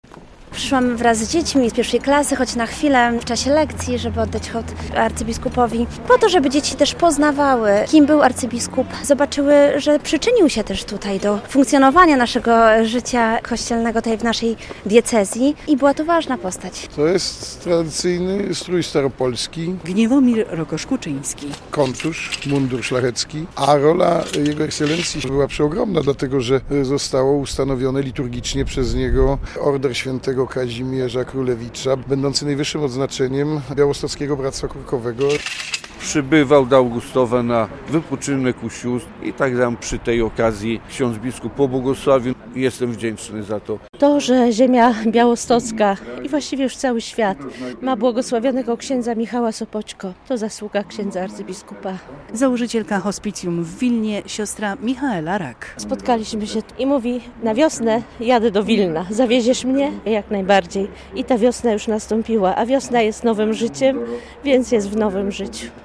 Mszy św. pogrzebowej Arcybiskupa Edwarda Ozorowskiego przewodniczył obecny metropolita białostocki abp Józef Guzdek, w asyście wielu kościelnych hierarchów.